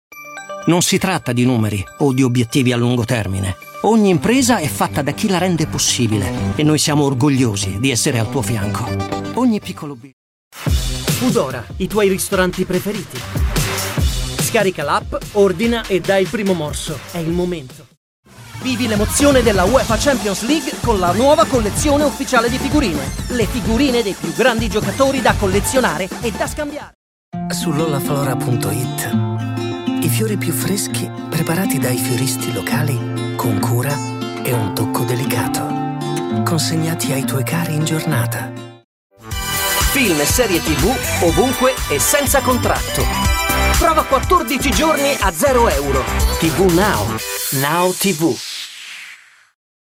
Male
young adult male,
smooth
Television Spots
Dynamic Italian Commercial